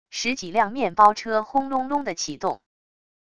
十几辆面包车轰隆隆的启动wav音频